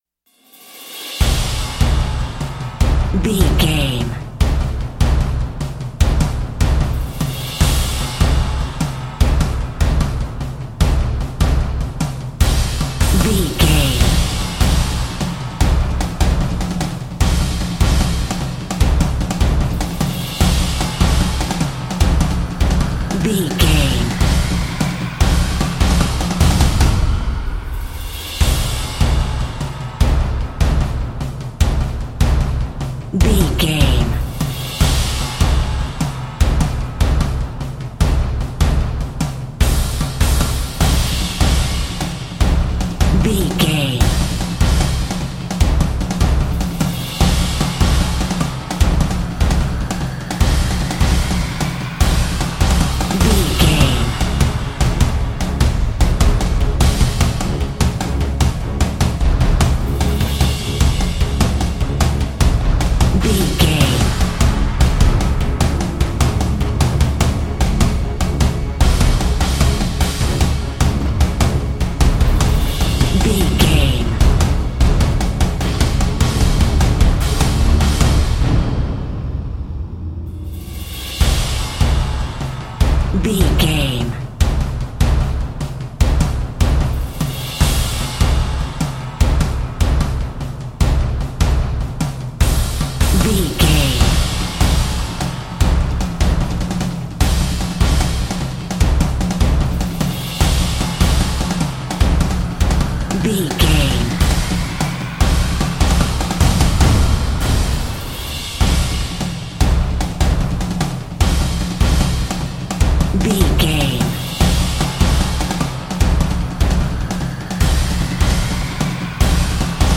Fast paced
In-crescendo
Aeolian/Minor
orchestral hybrid
dubstep
aggressive
energetic
intense
strings
drums
synth effects
wobbles
driving drum beat
epic